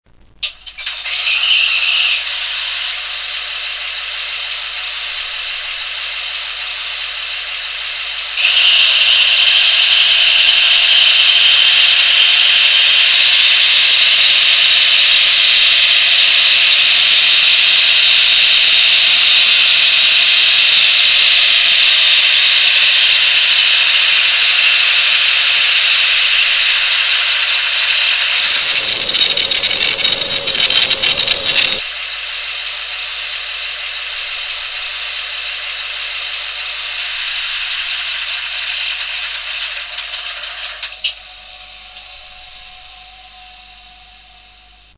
Estos sonidos se han grabado directamente del decoder o módulo una vez instalado en la locomotora.
TAFmotor.mp3